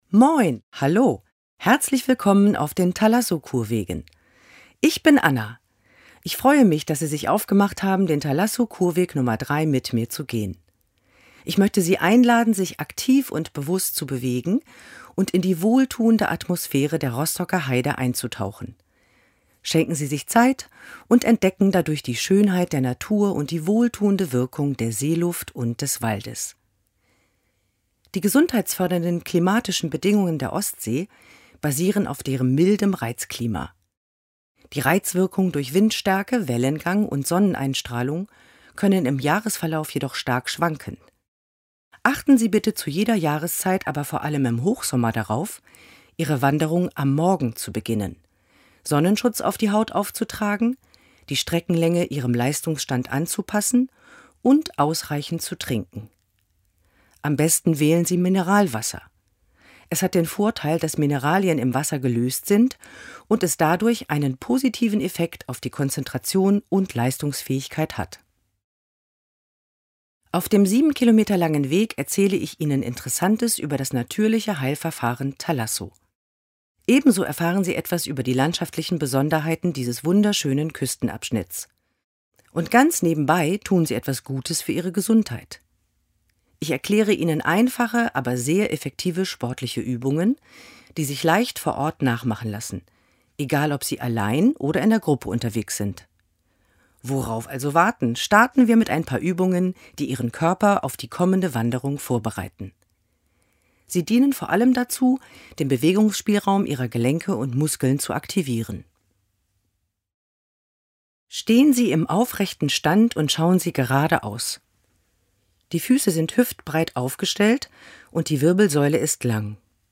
Audioguide
audio-de-tkw-003-01-einleitung-und-mobilisation.mp3